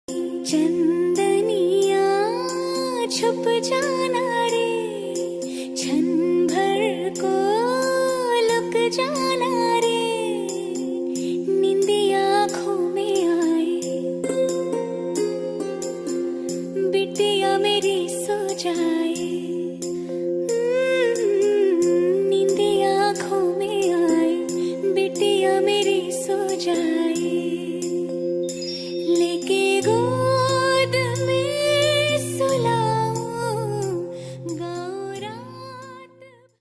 Emotional Ringtone || Sad Ringtone